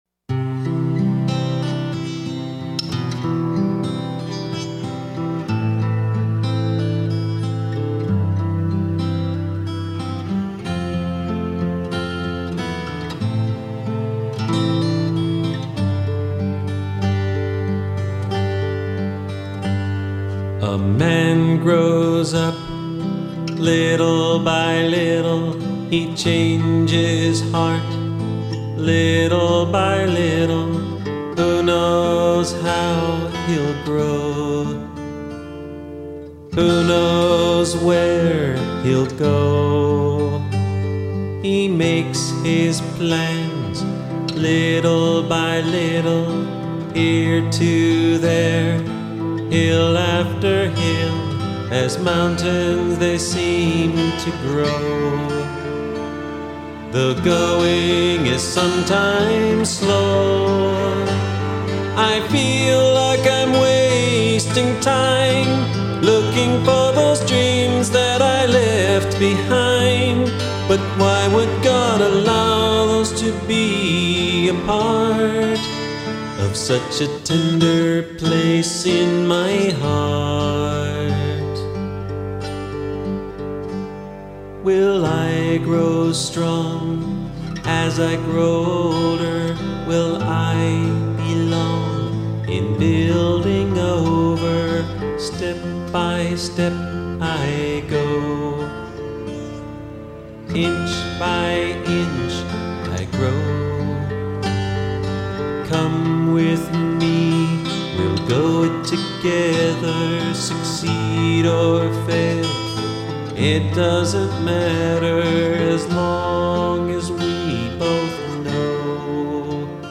This was the first album that we recorded in our own studio.